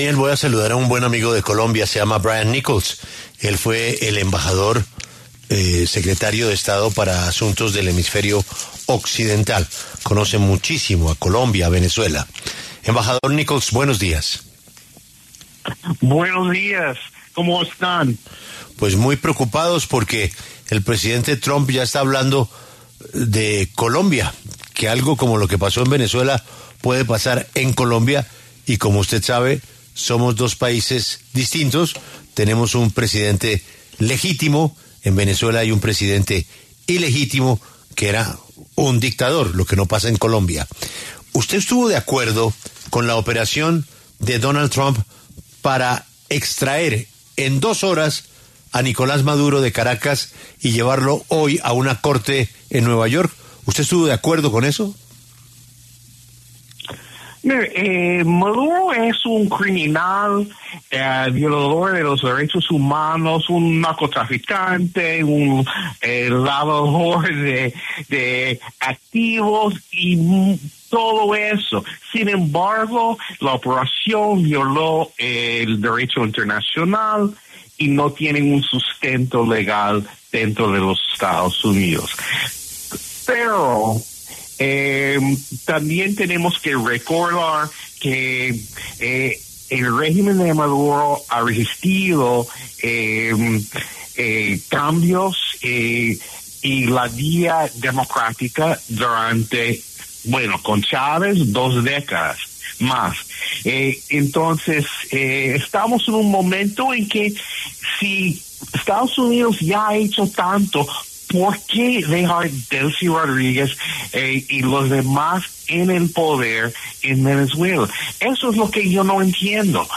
Brian Nichols, exsubsecretario de Estado para Asuntos del Hemisferio Occidental durante el gobierno de Joe Biden, habló este lunes en los micrófonos de La W, con Julio Sánchez Cristo, para referirse a la captura de Nicolás Maduro que se dio en el marco de una operación militar de Estados Unidos dentro de Venezuela.